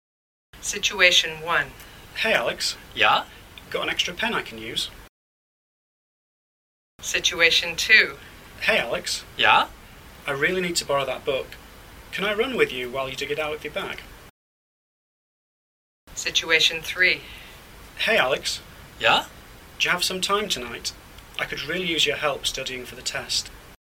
Ch4 - Follow-up Activity 4 - Natural - No Repeat.mp3
Ch4-Follow-upActivity-Natural-NoRepeat.mp3